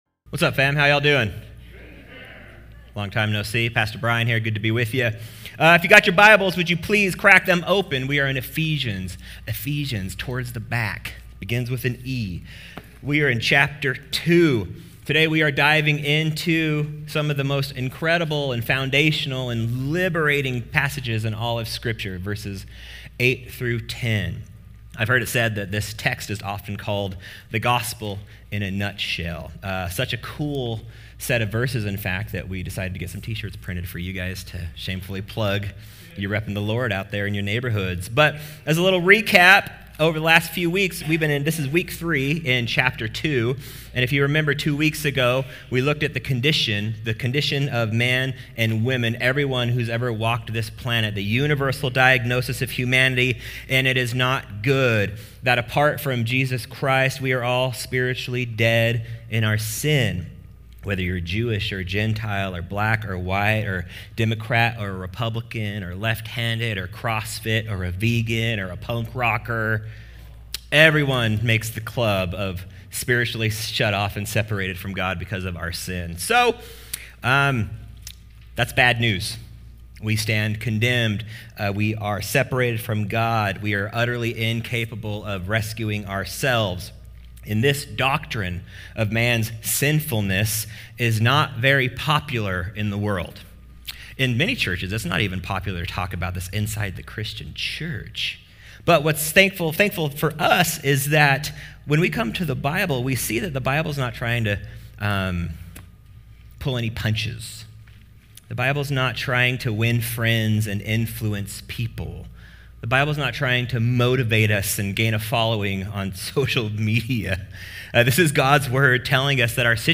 Recent Messages - The Rock Church